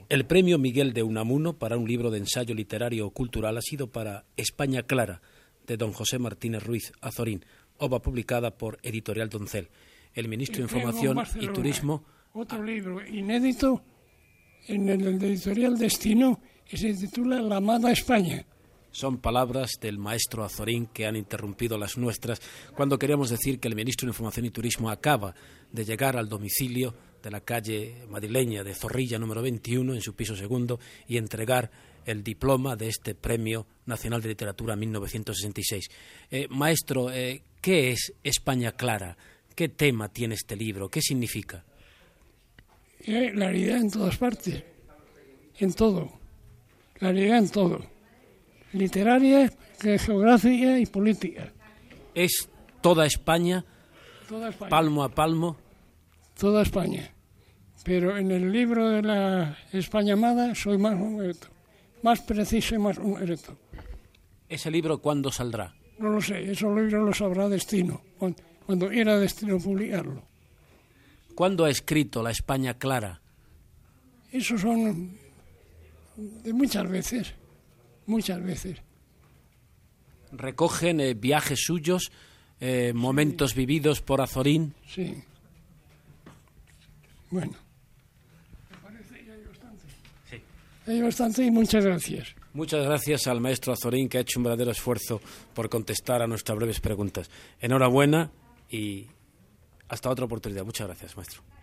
Entrevista a l'escriptor José Martínez Ruiz "Azorín" , qui rep a casa el diploma del premi Nacional de Literatura per l'obra "España clara".
Aquesta va ser l'última entrevista a Azorín a RNE.
Extret del programa "El sonido de la historia", emès per Radio 5 Todo noticias el 9 de març de 2013.